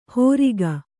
♪ hōriga